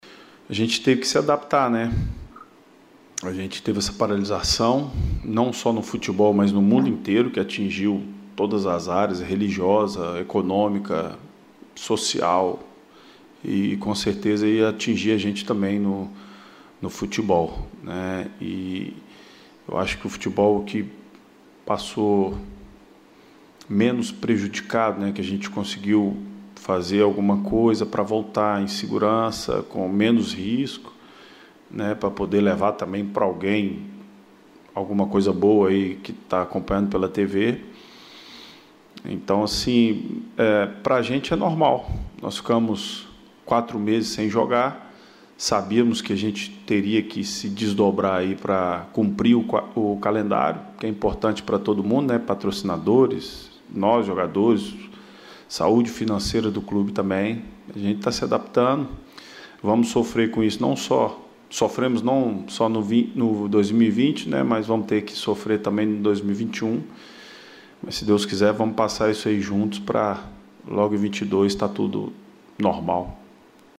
O atacante participou da videoconferência desta terça-feira (05.01) com os jornalistas e falou sobre este tema e outros assuntos importantes.